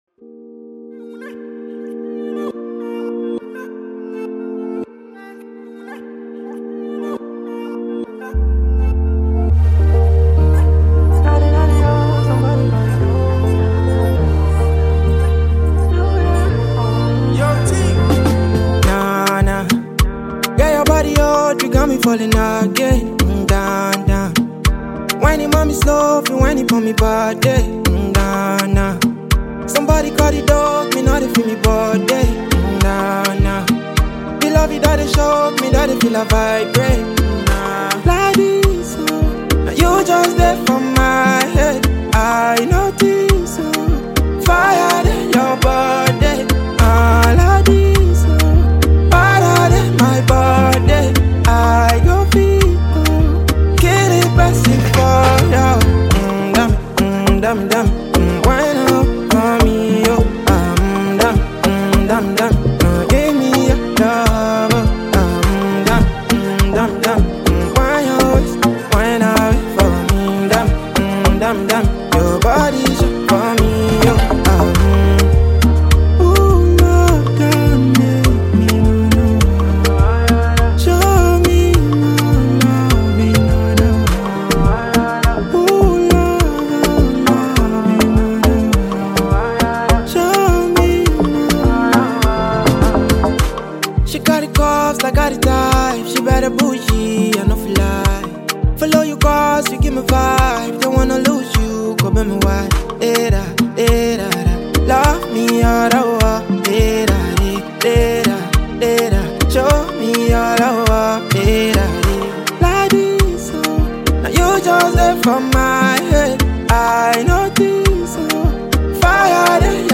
high vibe melody
Afrobeats